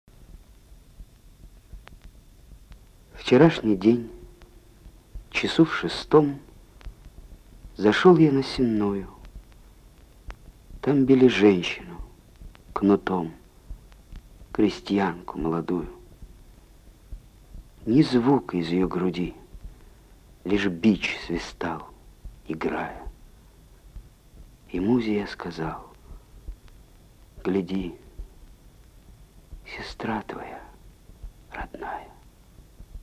7. «Н.А. Некрасов – Вчерашний день часу в шестом (читает А. Калягин) Страницы русской поэзии» /